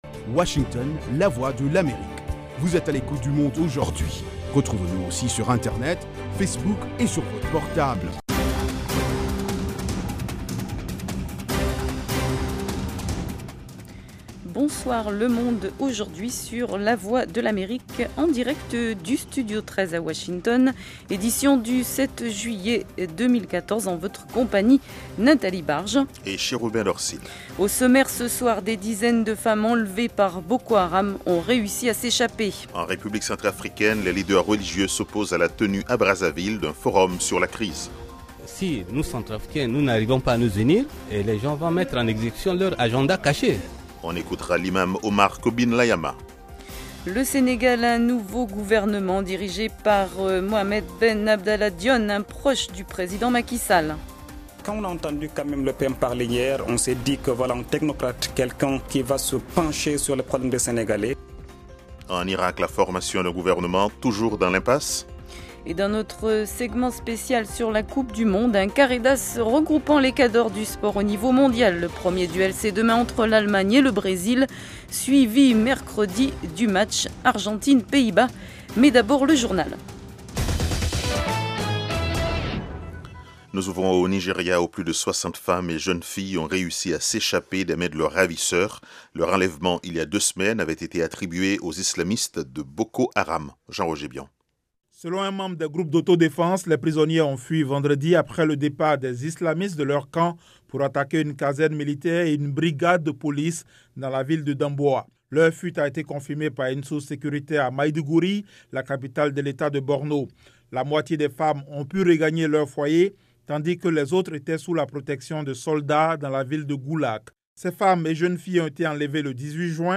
Toute l’actualité sous-régionale sous la forme de reportages et d’interviews.
Le Monde aujourd'hui, édition pour l'Afrique de l’Ouest, c'est aussi la parole aux auditeurs pour commenter à chaud les sujets qui leur tiennent à coeur.